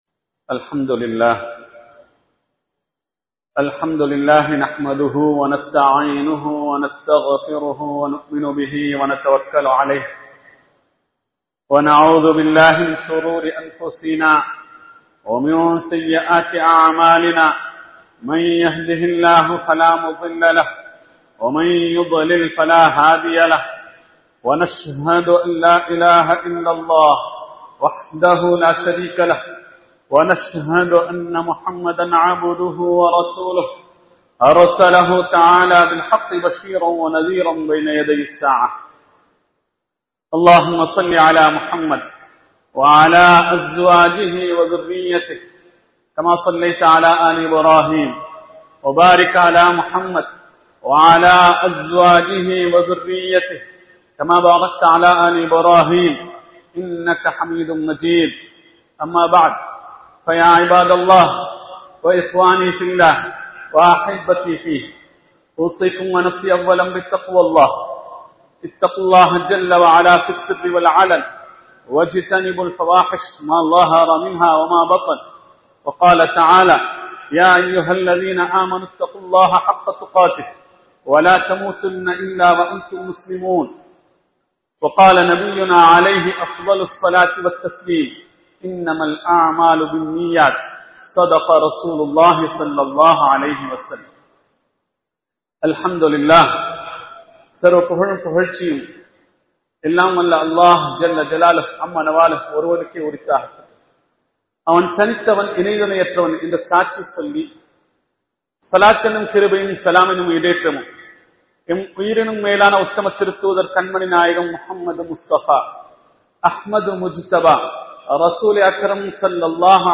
இஸ்லாம் வலியுறுத்தும் சுத்தம் | Audio Bayans | All Ceylon Muslim Youth Community | Addalaichenai
Panadura, Pallimulla Jumua Masjith